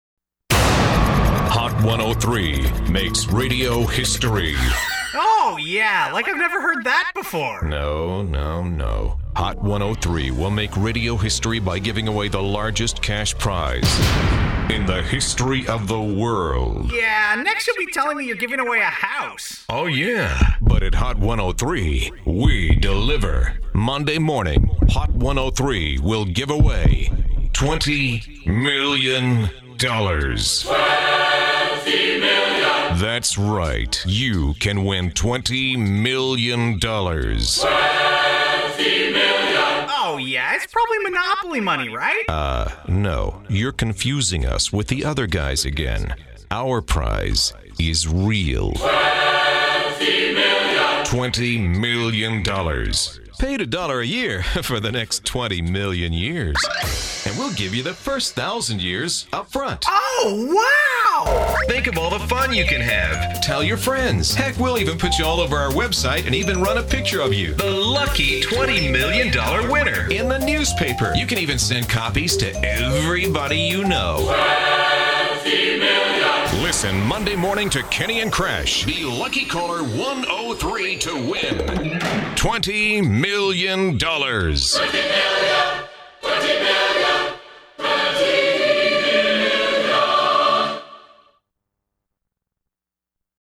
Hot 103 commercial “Twenty Million”